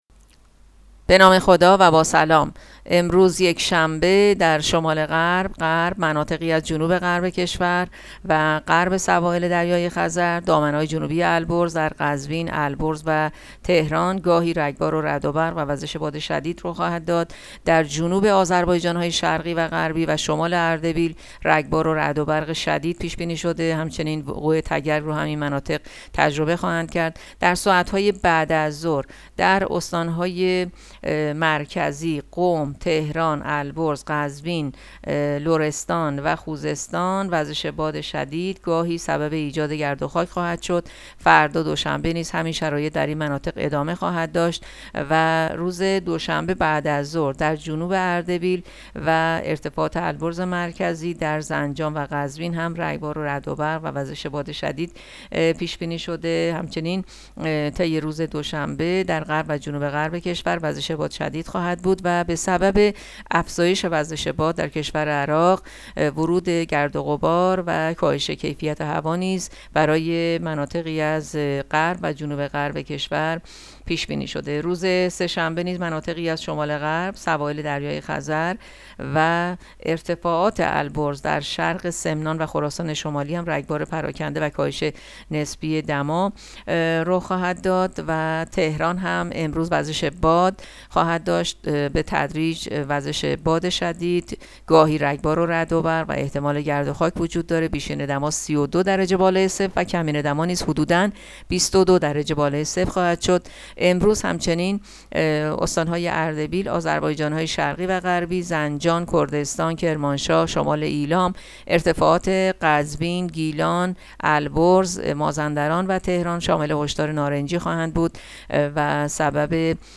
گزارش رادیو اینترنتی پایگاه‌ خبری از آخرین وضعیت آب‌وهوای چهاردهم اردیبهشت؛